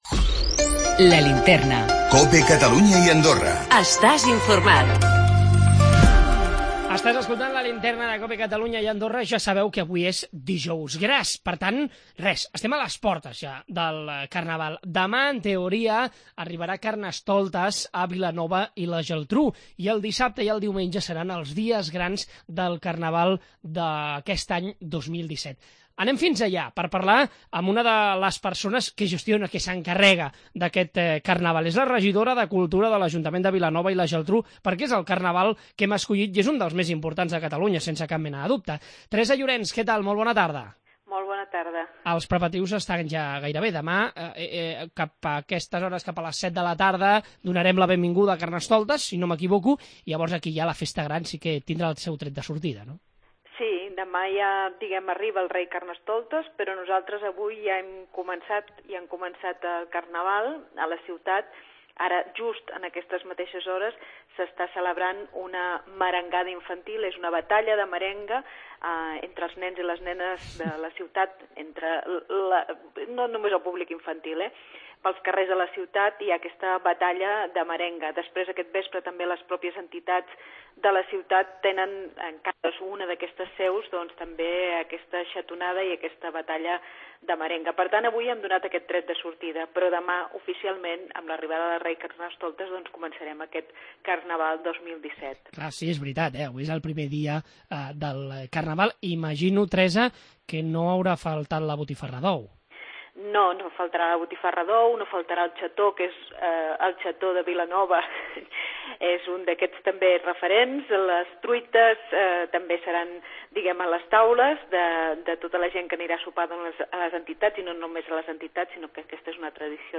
Diumenge el dia gran amb les Comparses. En parlem ara amb Teresa Llorens, regidora de Cultura!